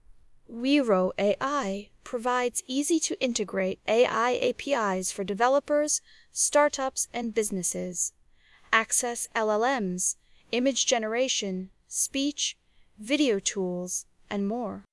VibeVoice-Realtime is a lightweight real‑time text-to-speech model supporting streaming text input and robust long-form speech generation.